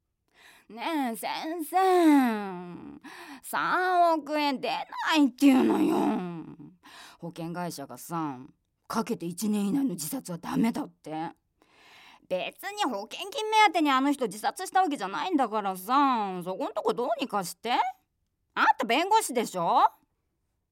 セリフB
ボイスサンプル